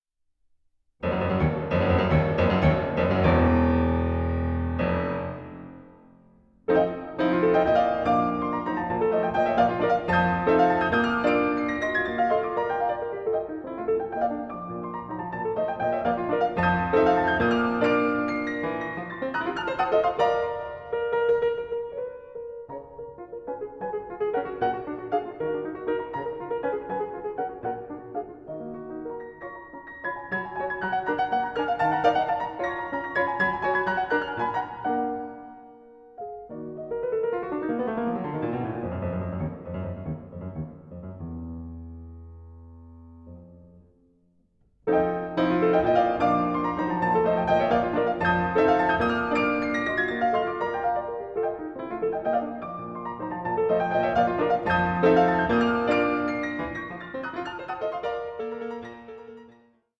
INTROSPECTIVE PIECES ABOUT LOVE IN ALL ITS MANIFESTATIONS